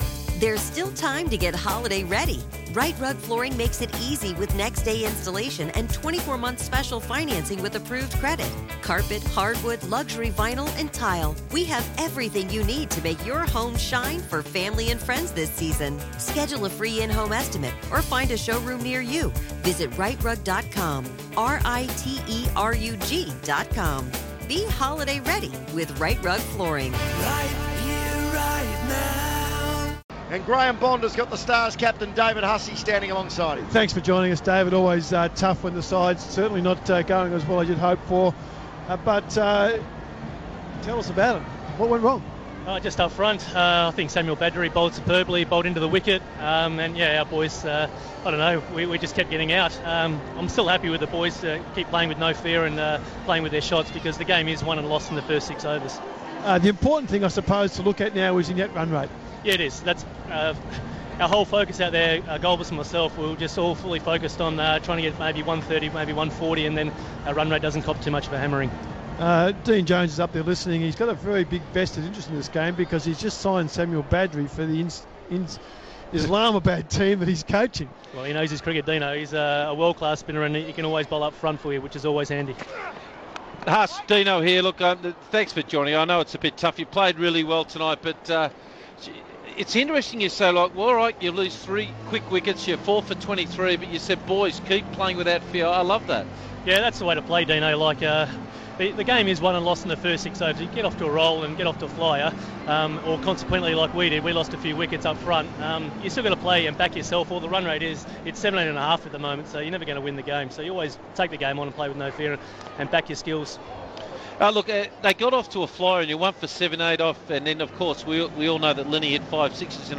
LISTEN: Stars captain David Hussey speaks to Dean Jones